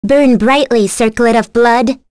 Lewsia_A-Vox_Skill3.wav